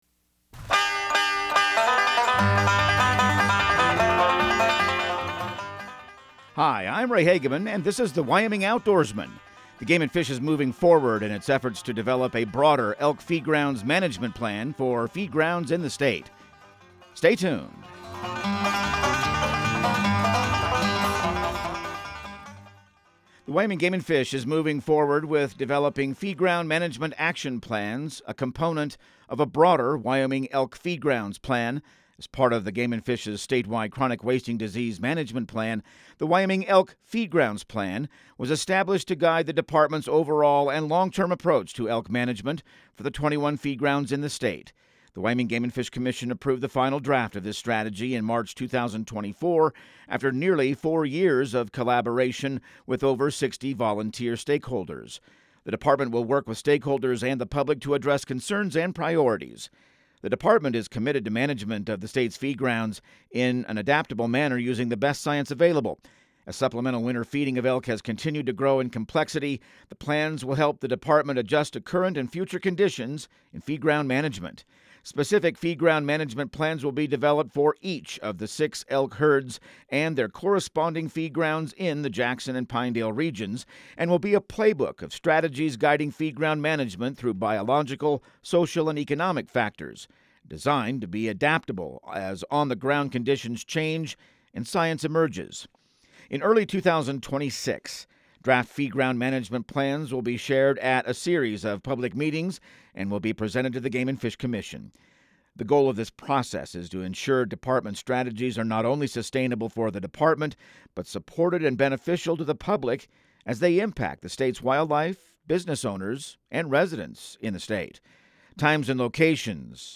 Radio news | Week of January 20